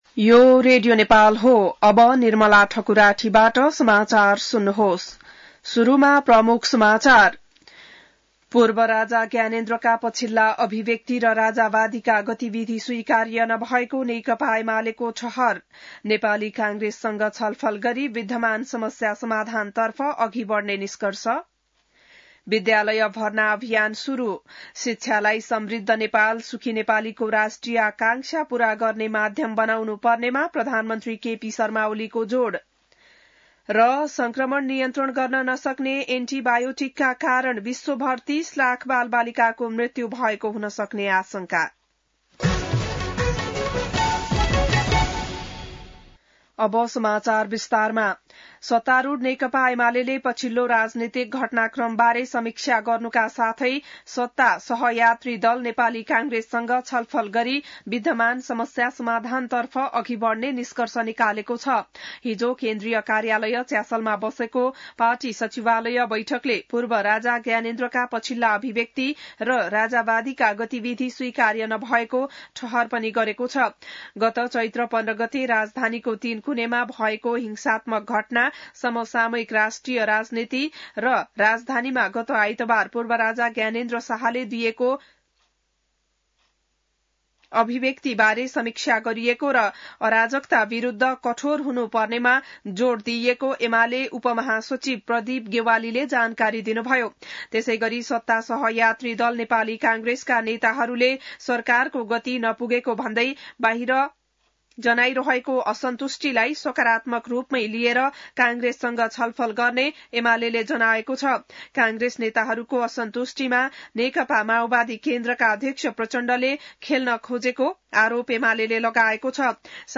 बिहान ९ बजेको नेपाली समाचार : २ वैशाख , २०८२